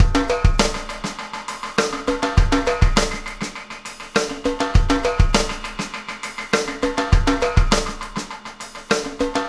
Pulsar Beat 07.wav